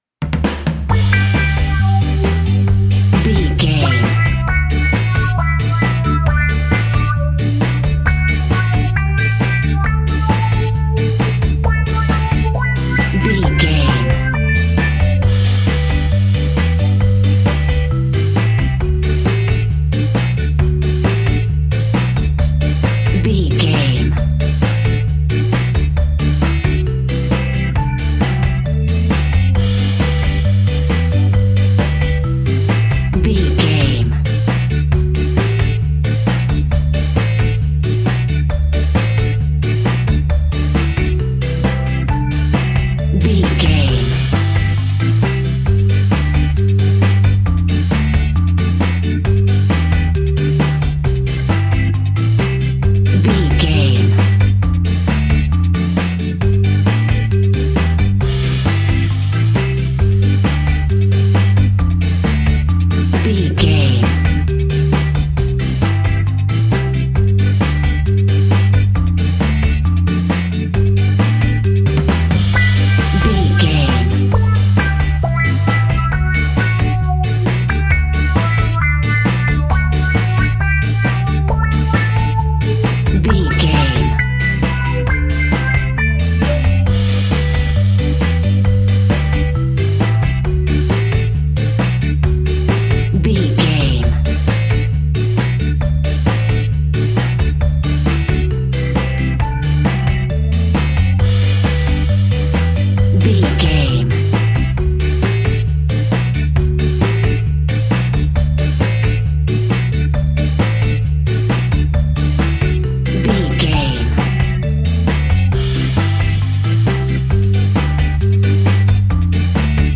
Ionian/Major
reggae
dub
laid back
chilled
off beat
drums
skank guitar
hammond organ
transistor guitar
percussion
horns